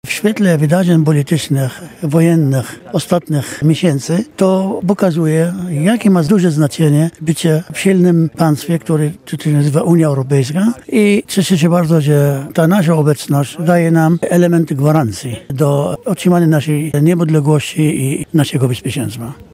Bialczanie świętują 18. rocznicę przystąpienia Polski do Unii Europejskiej. Z tej okazji w Parku Radziwiłłowskim odbywa się piknik rodzinny pod hasłem „Kocham Cię Europo”.
Współorganizator pikniku, poseł Riad Haidar, podkreśla, że w kontekście ostatnich wydarzeń możemy przekonać się, jakie znaczenie miało przystąpienie Polski do Unii Europejskiej.